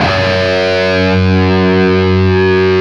LEAD F#1 CUT.wav